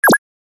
جلوه های صوتی
دانلود صدای موس 4 از ساعد نیوز با لینک مستقیم و کیفیت بالا
برچسب: دانلود آهنگ های افکت صوتی اشیاء دانلود آلبوم صدای کلیک موس از افکت صوتی اشیاء